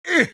attack2.ogg